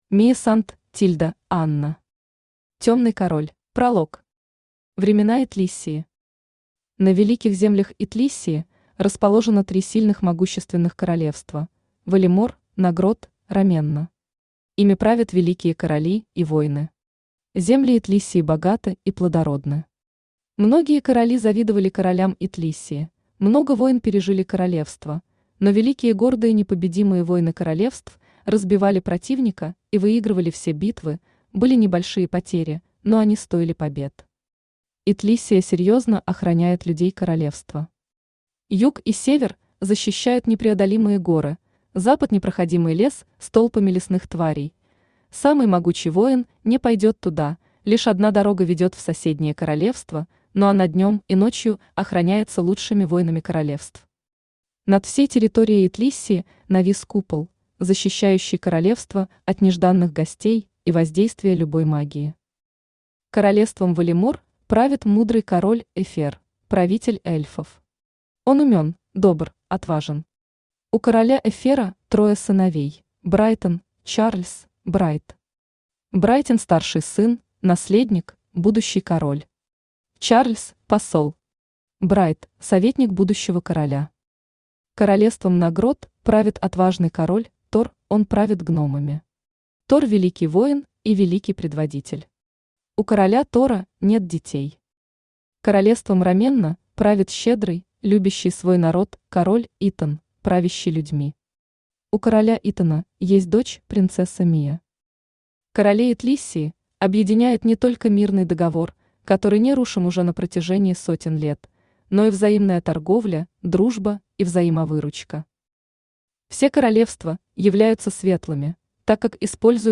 Аудиокнига Темный король | Библиотека аудиокниг
Aудиокнига Темный король Автор Мия Сант~Анна Читает аудиокнигу Авточтец ЛитРес.